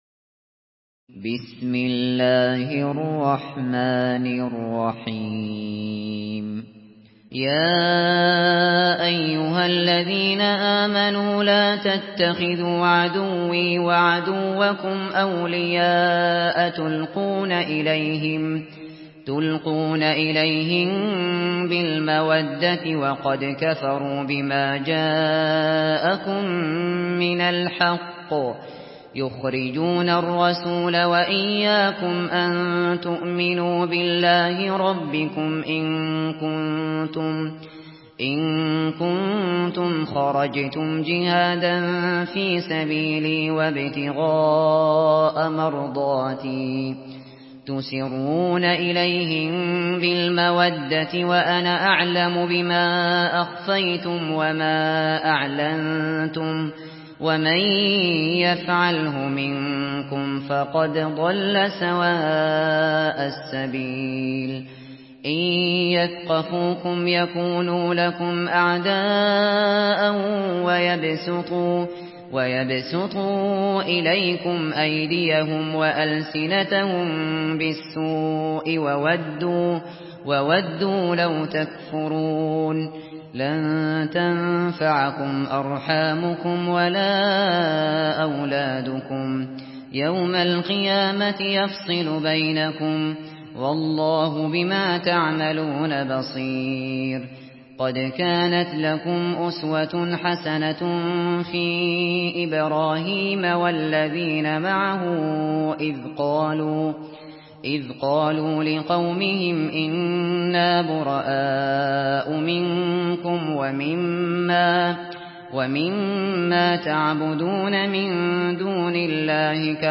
Surah Al-Mumtahinah MP3 by Abu Bakr Al Shatri in Hafs An Asim narration.
Murattal Hafs An Asim